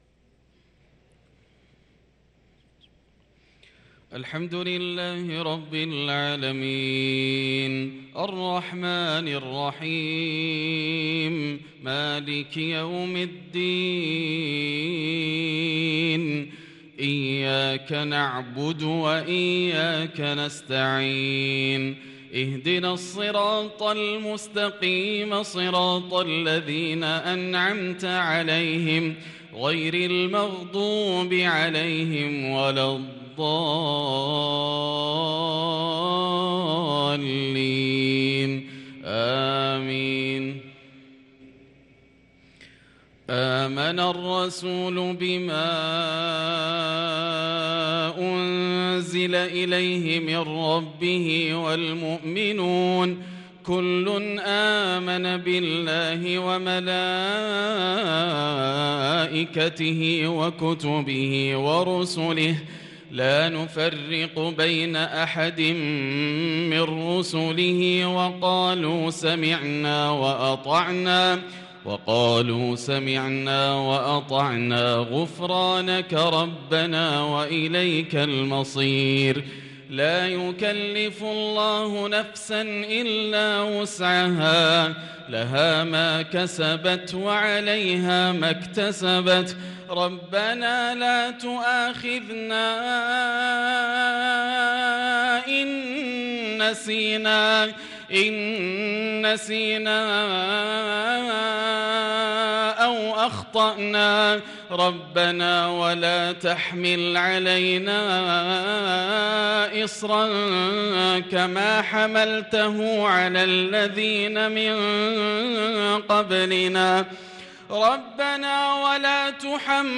صلاة المغرب للقارئ ياسر الدوسري 14 ذو الحجة 1443 هـ
تِلَاوَات الْحَرَمَيْن .